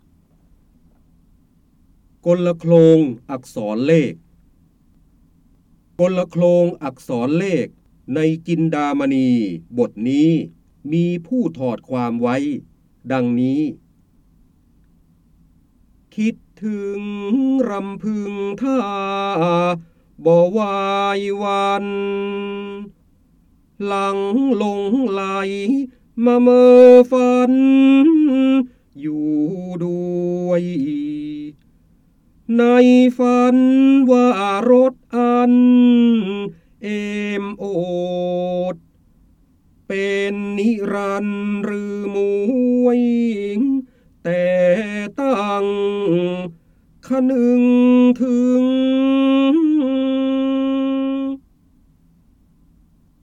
เสียงบรรยายจากหนังสือ จินดามณี (พระโหราธิบดี) กลโคลงอักษรเลข
คำสำคัญ : ร้อยกรอง, ร้อยแก้ว, พระเจ้าบรมโกศ, พระโหราธิบดี, การอ่านออกเสียง, จินดามณี
ลักษณะของสื่อ :   คลิปการเรียนรู้, คลิปเสียง